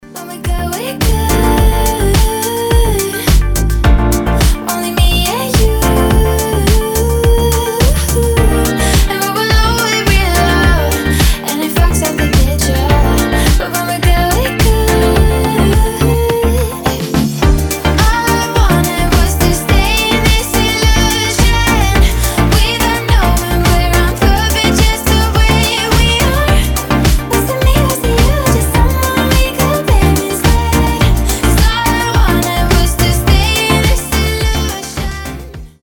женский вокал
приятные
nu disco
красивый женский голос
Indie Dance
Фрагмент очень приятной песни в стиле ню диско